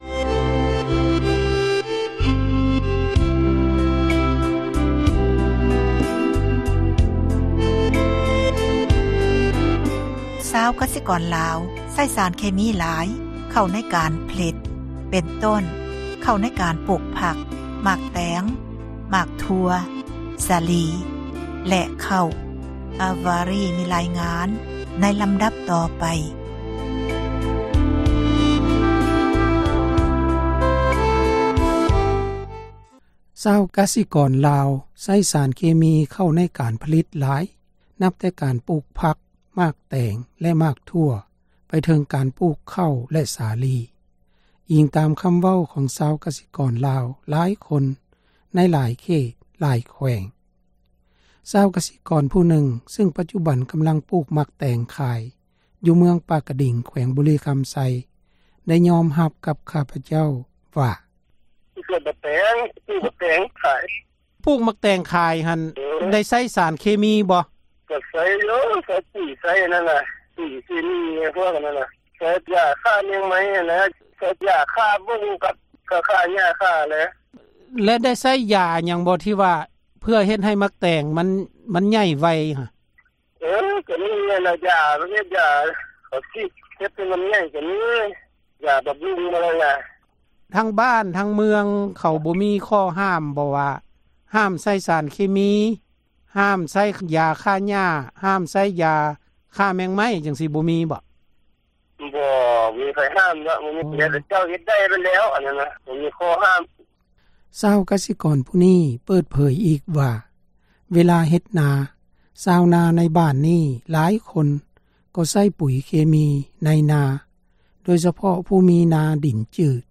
ຊາວກະສິກອນຜູ້ນຶ່ງ ຊຶ່ງປັດຈຸບັນ ກຳລັງປູກໝາກແຕງ ຂາຍ ຢູ່ເມືອງປາກກະດິງ ແຂວງບໍຣິຄຳໄຊ ໄດ້ຍອມຮັບກັບຂ້າພະເຈົ້າວ່າ: